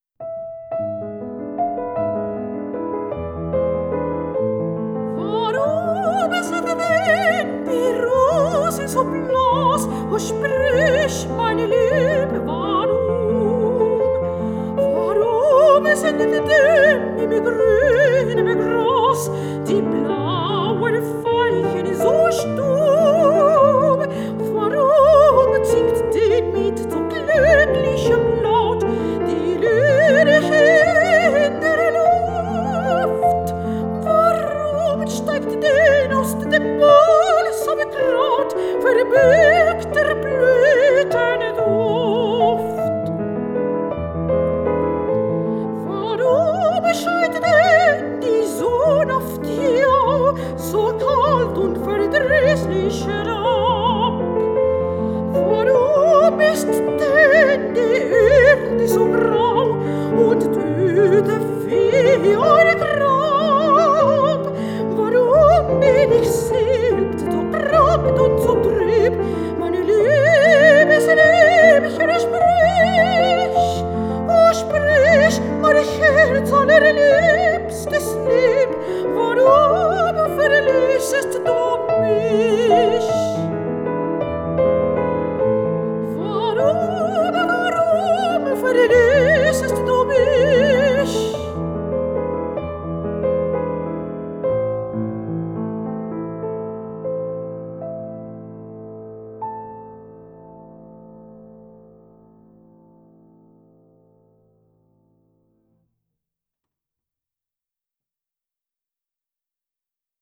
Mujeres y género: Clara Wiek y Fanny Mendelssohn, compilación de obras escritas para voz y piano
El objetivo fundamental de esta grabación es la difusión de obras de compositoras activas durante el periodo romántico de Alemania, desde una perspectiva de la interpretación históricamente informada.
Fanny Mendelssohn, Romanticismo Alemán, Género Lied